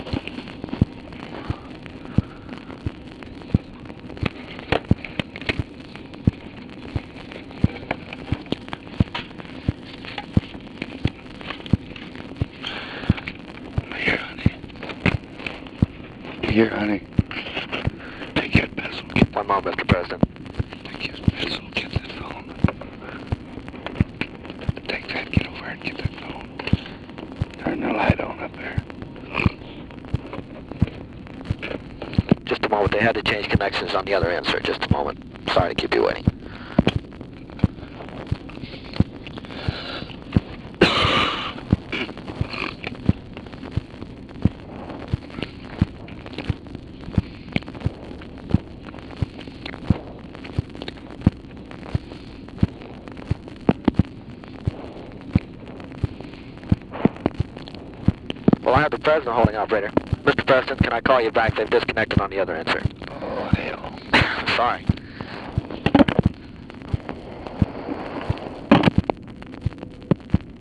LBJ IS ON HOLD FOR TELEPHONE CALL TO UNIDENTIFIED PERSON WHICH IS DISCONNECTED; WHILE ON HOLD LBJ GIVES INSTRUCTIONS TO SOMEONE IN THE ROOM WITH HIM
Conversation with OFFICE CONVERSATION